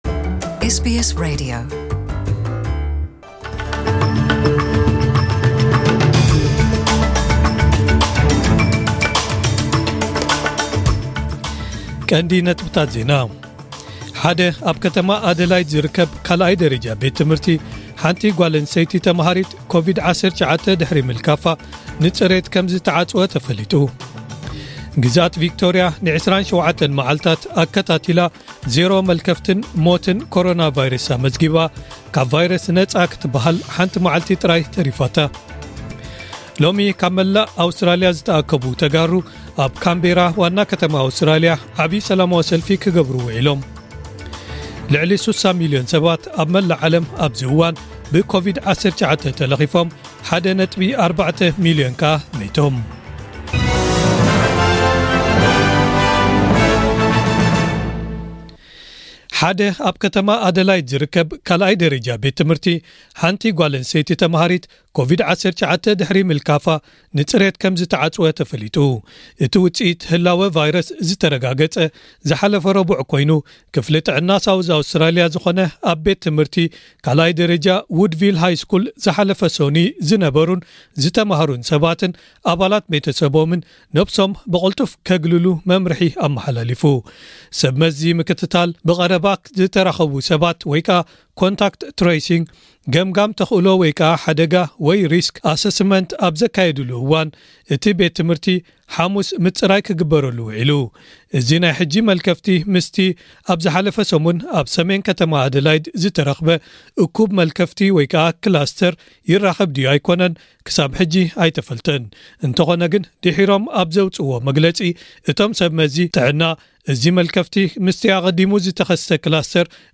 ዕለታዊ ዜና ኤስቢኤስ ትግርኛ (26/11/2020) * ሓደ ኣብ ከተማ ኣደላይድ ዝርከብ ካልኣይ ደረጃ ቤት ትምህርቲ ሓንቲ ጓል ኣንስተይቲ ተማሃሪት ኮቪድ-19 ድሕሪ ምልካፋ ንጽሬት ከምዝተዓጽወ ተፈሊጡ። * ግዝኣት ቪክቶርያ ን27 መዓልታት ኣከቲታላ ዜሮ መልከፍትን ሞትን ኮሮናቫይረስ ኣመዝጊባ።